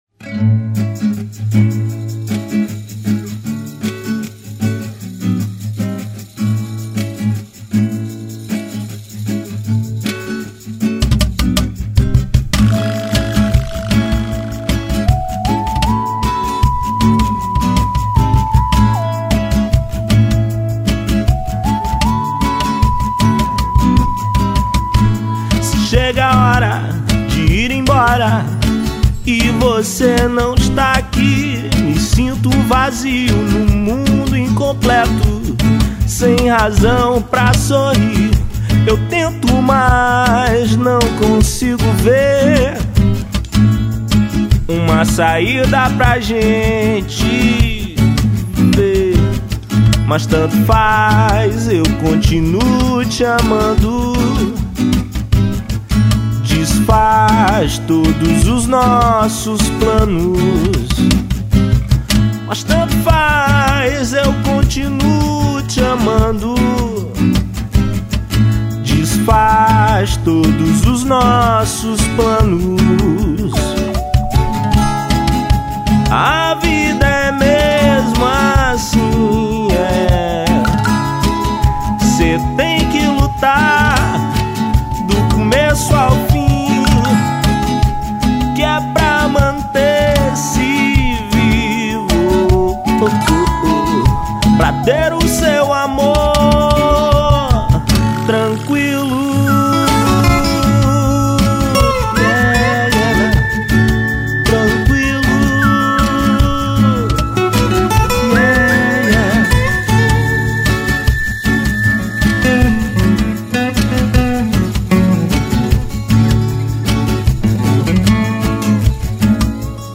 1975   03:50:00   Faixa:     Reggae